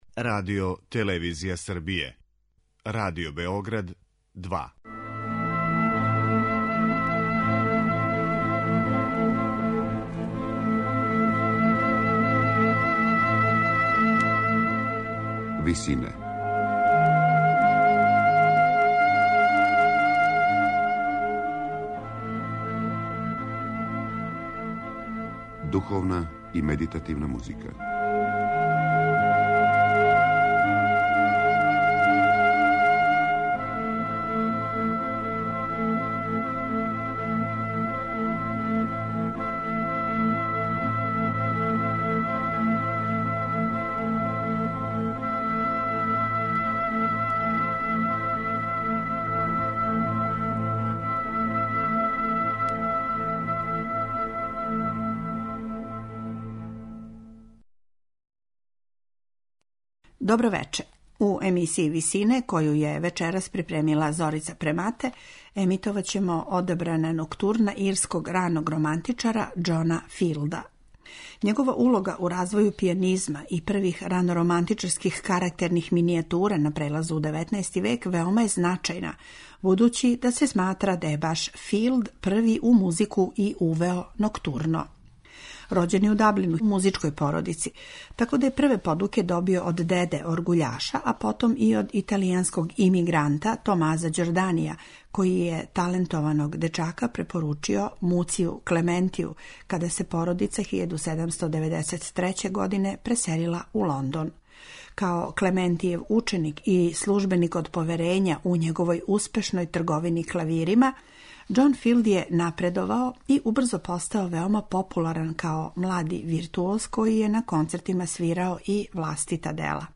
медитативне и духовне композиције
Слушаћете ноктурна ирског раног романтичара Џона Филда, који је ову карактерну форму и уврстио у уметничку музику.
пијаниста